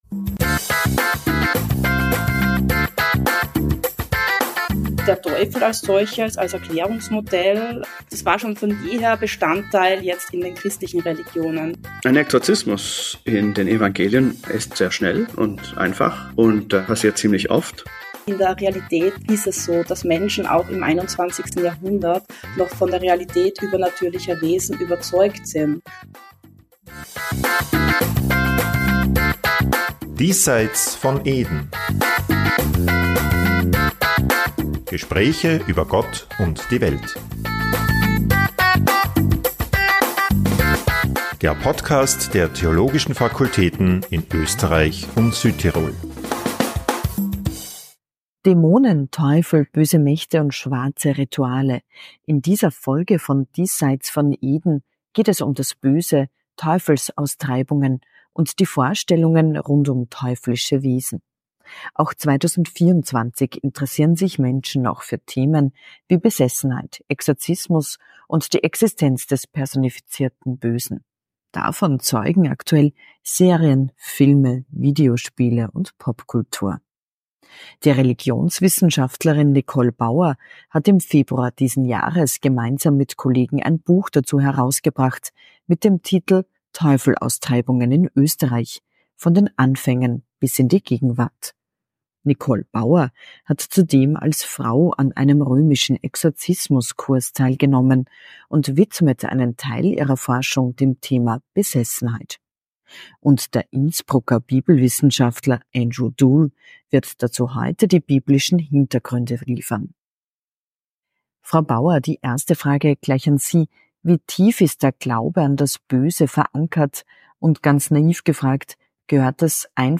Ein Gespräch über die tatsächliche Wirkung von Exorzismen und Engelsprays sowie biblische Vorstellungen vom Bösen.